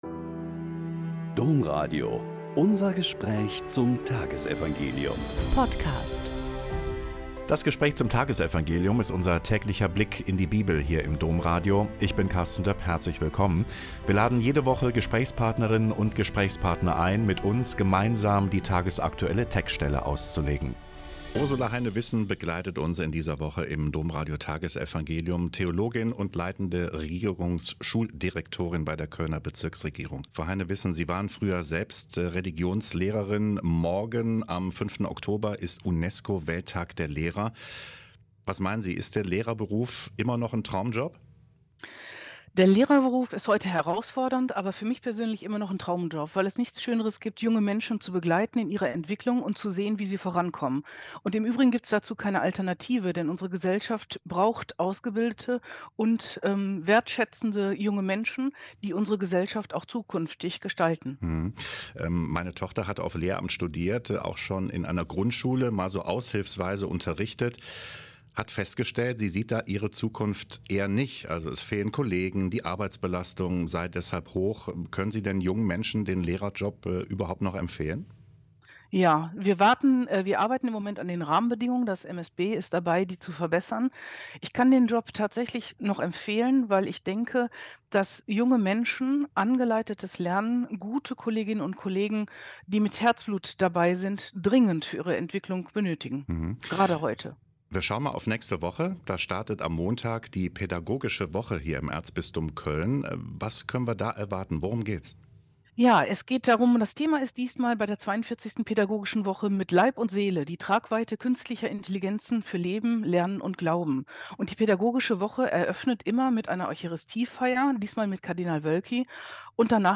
Lk 10,13-16 - Gespräch